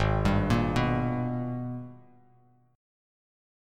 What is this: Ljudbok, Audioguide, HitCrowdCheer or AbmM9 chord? AbmM9 chord